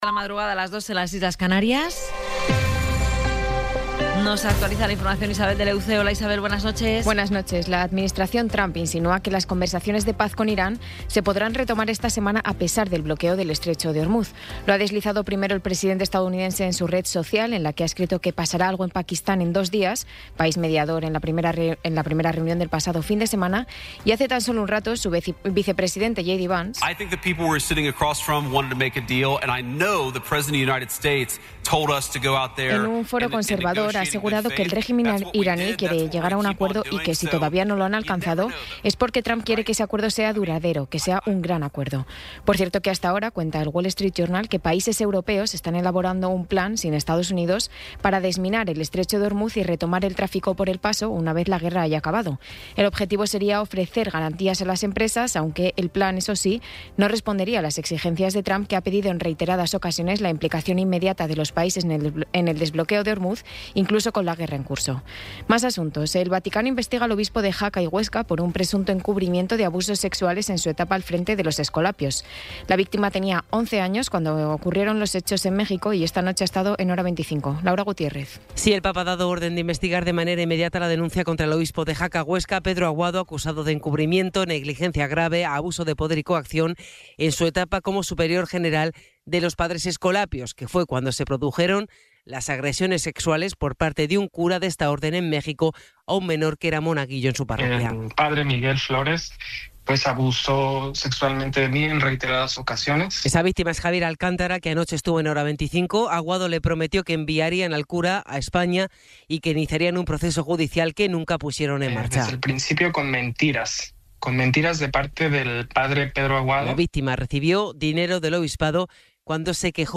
Resumen informativo con las noticias más destacadas del 15 de abril de 2026 a las tres de la mañana.